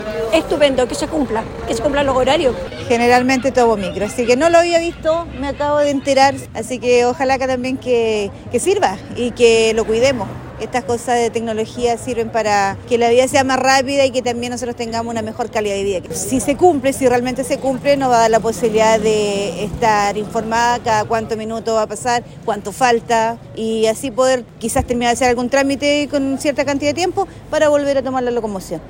Usuarias que esperaban la locomoción en el paradero de Avenida Colón en la comuna puerto agradecieron la iniciativa.